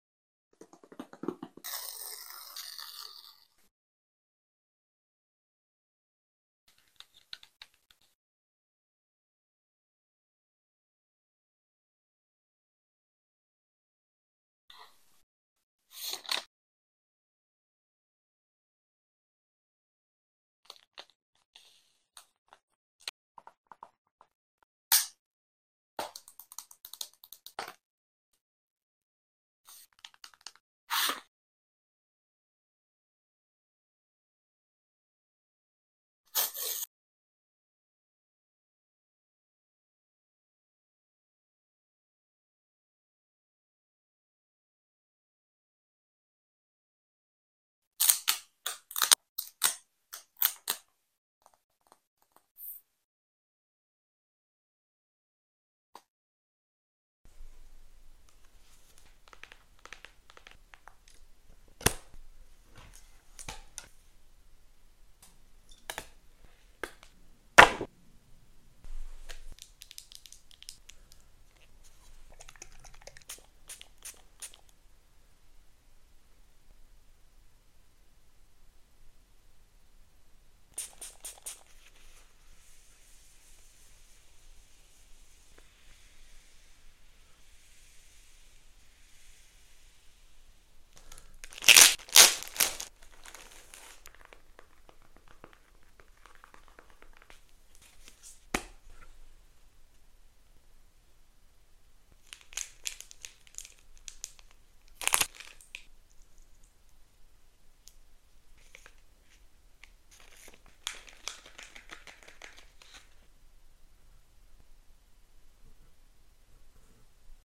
Here’s a long version/compilation of sound effects free download